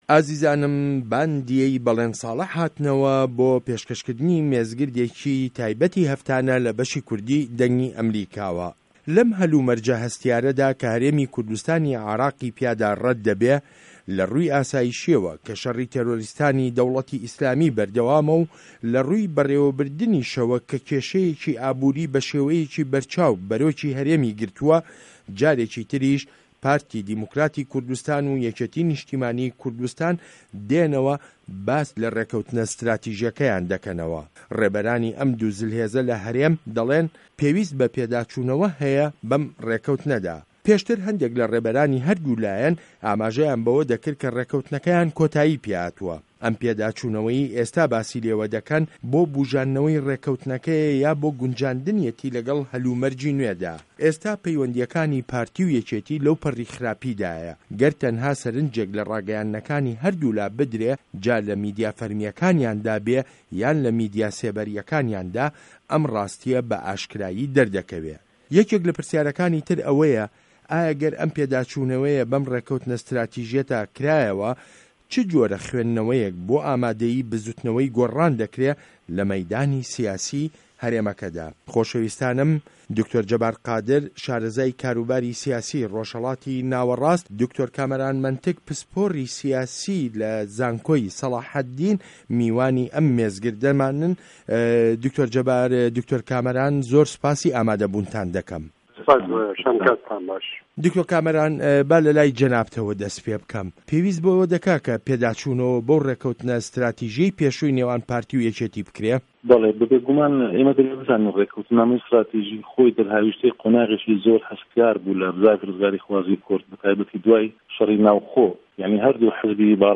مێزگرد: پێداچوونه‌وه‌ به‌ ڕێکه‌وتنه‌ ستراتیژیه‌که‌ی پارتی و یه‌کێتیدا